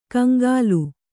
♪ kaŋgālu